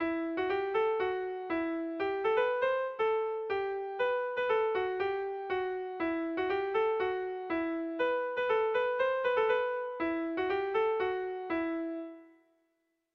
Erlijiozkoa
ABD